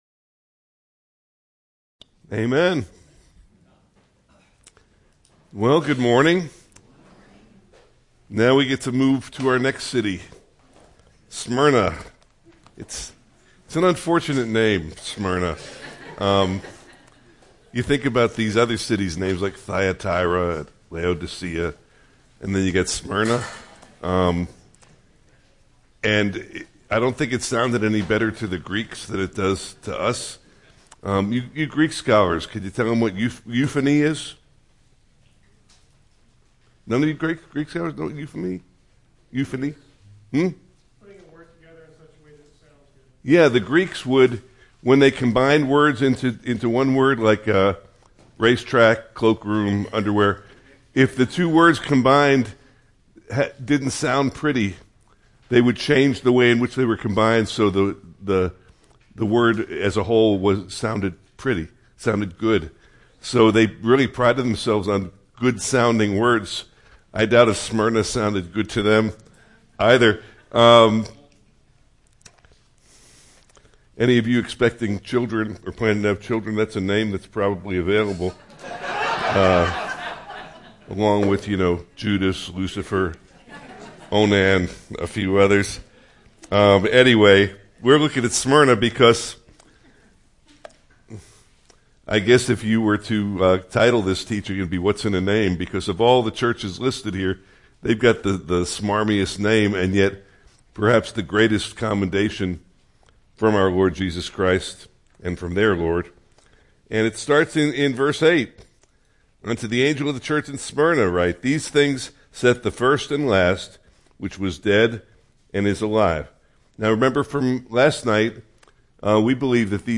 Part 2 of a series of verse-by-verse teachings on the opening chapters of the book of Revelation. There is great practical learning for us in the messages from our Lord to the churches.